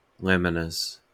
Ääntäminen
Vaihtoehtoiset kirjoitusmuodot laminae Ääntäminen US Haettu sana löytyi näillä lähdekielillä: englanti Käännöksiä ei löytynyt valitulle kohdekielelle.